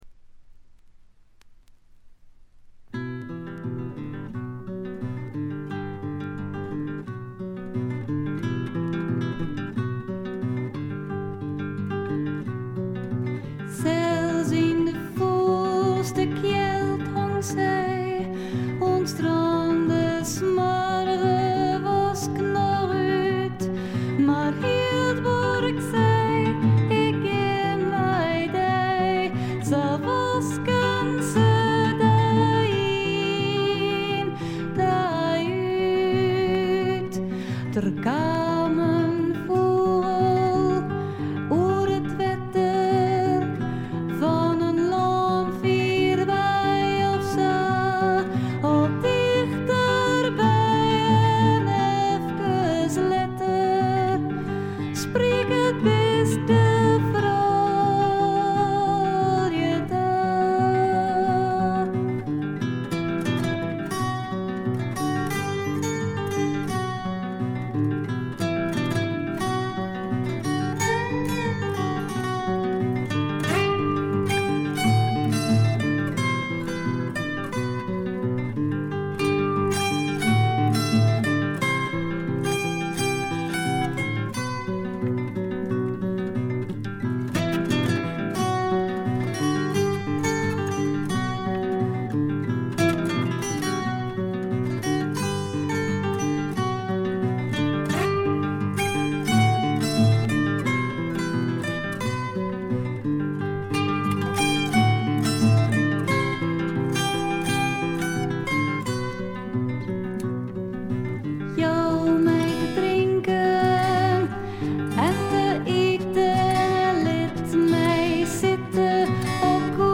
部分試聴ですが、ほとんどノイズ感無し。
アコースティック楽器主体ながら多くの曲でドラムスも入り素晴らしいプログレッシヴ・フォークを展開しています。
試聴曲は現品からの取り込み音源です。